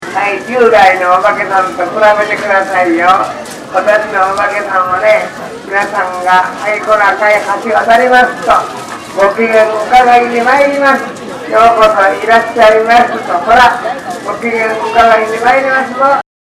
祭りの中MacBookを持って録音をしてきました
予想以上に内臓マイクの音質が良かったのですが、